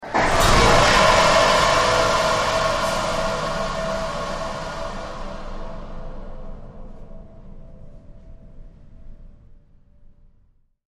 Shock Fire; Warning Beeps, Heavy Power Surge And Whoosh